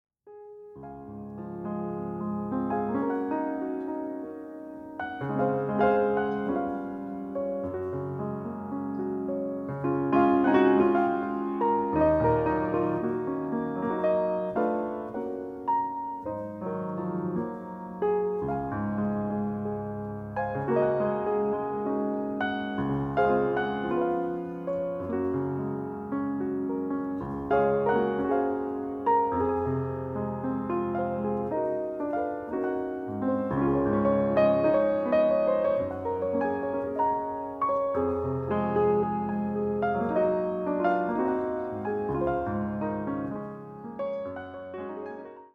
いわゆる「イージーリスニング」と呼ばれる、1920〜50年代にヒットした曲を中心とした選曲。
ハワイに行ったことがある人もない人も、優しい海風のようなこのピアノに、ただ身を任せてください。